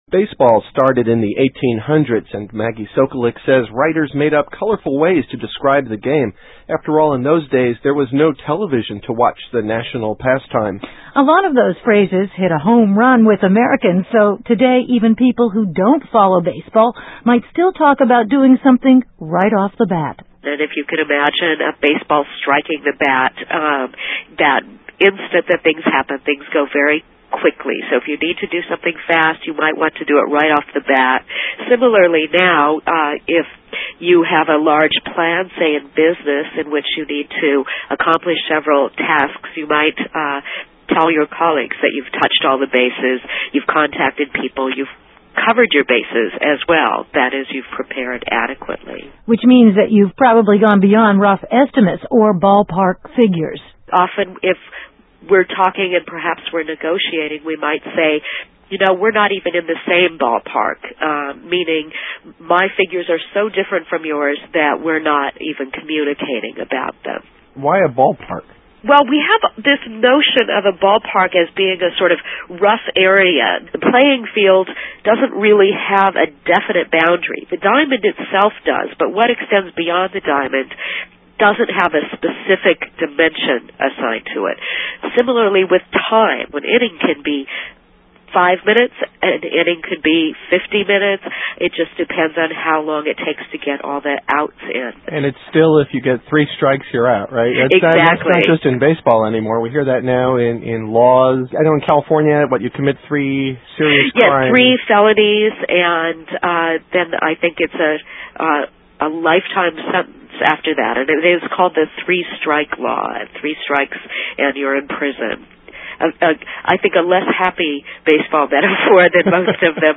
[Interview first aired on VOA in April 2001]